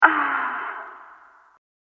ahhh.mp3